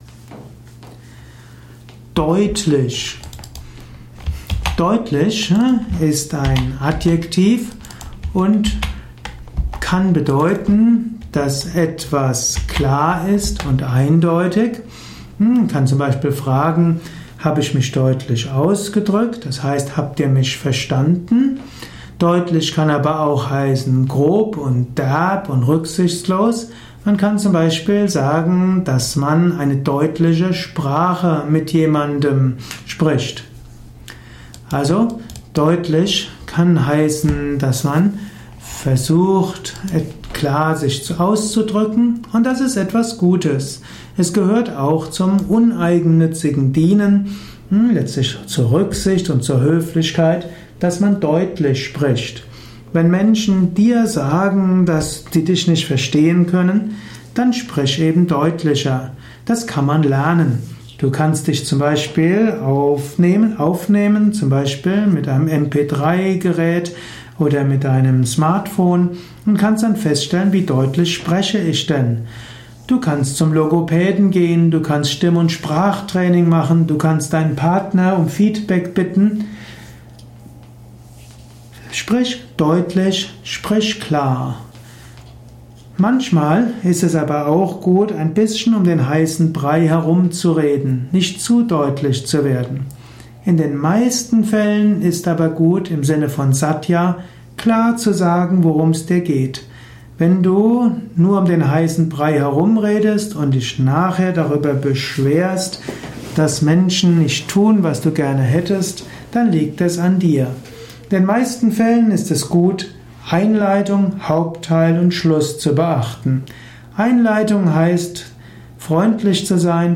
Einige Infos zum Thema Deutlich in diesem speziellen – oder nicht so speziellen – Audiovortrag.